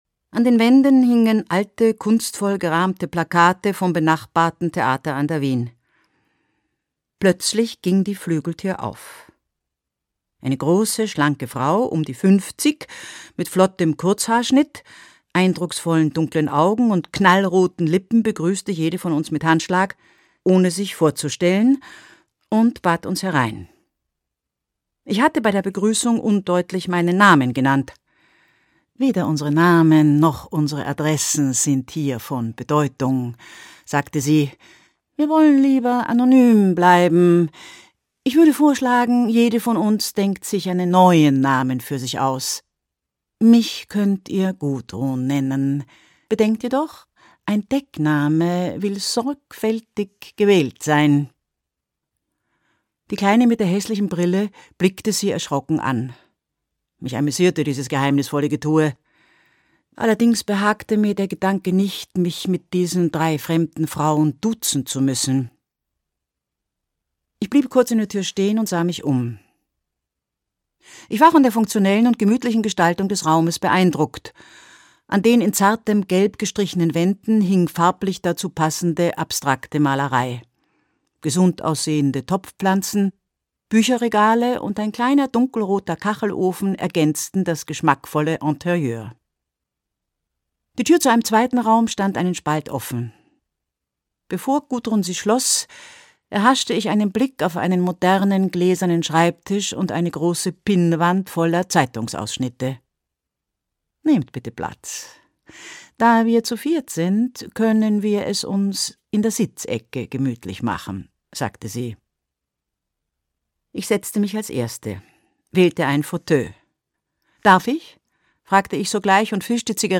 Krimi to go: Der Tod ist eine Wienerin - Edith Kneifl - Hörbuch - Legimi online